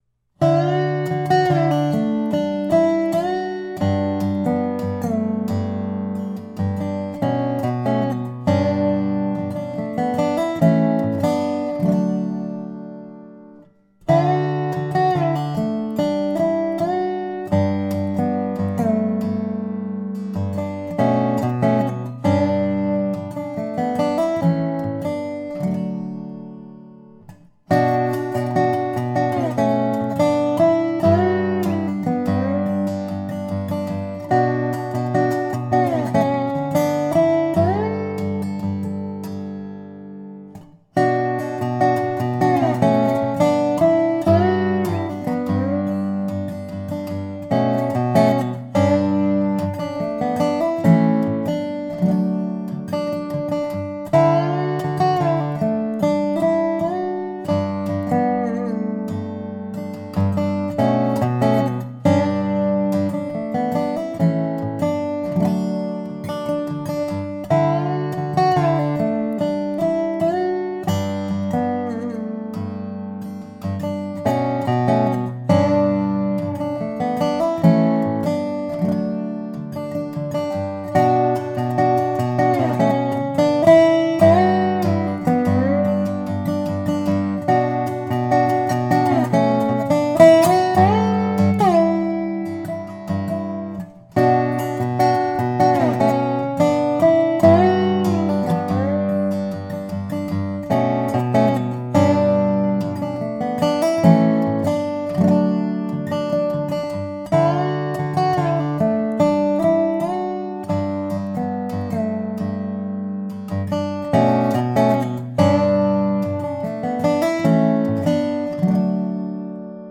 Solo Dobro Part 1